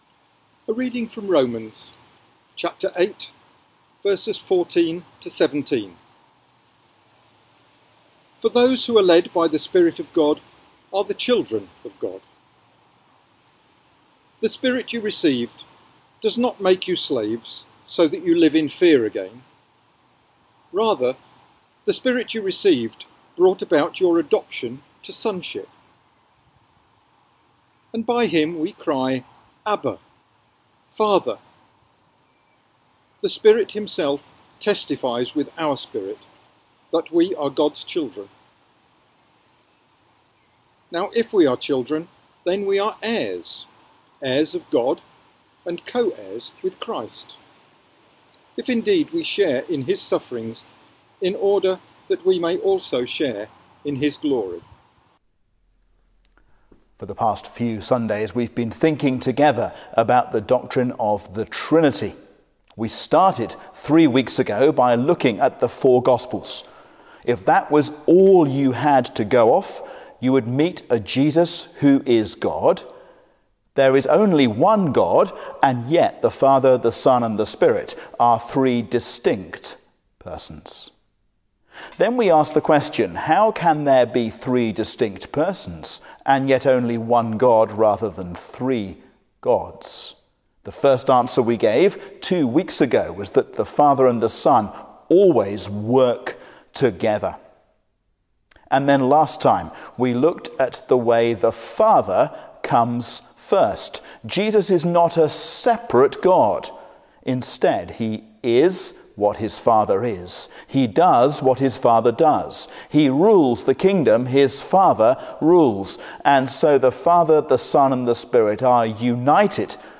Joining the Family, A sermon on Romans 8:14-17